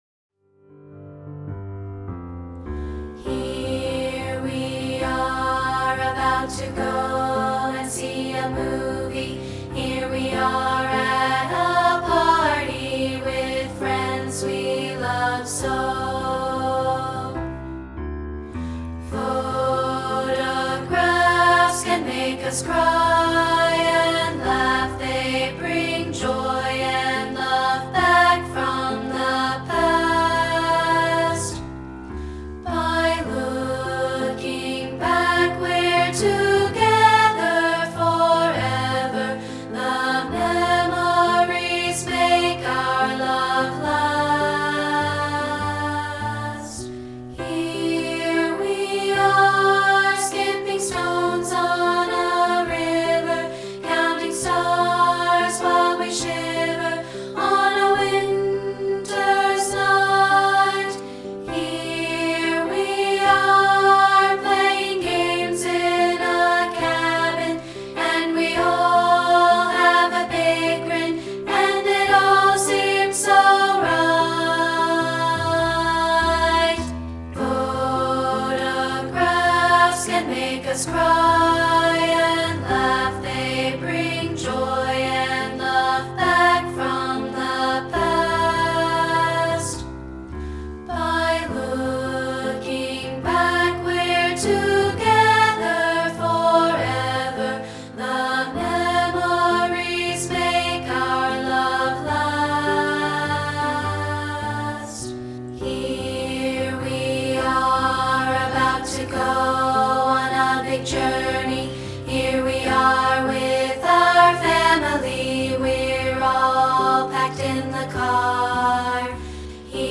including a rehearsal track of part 2, isolated.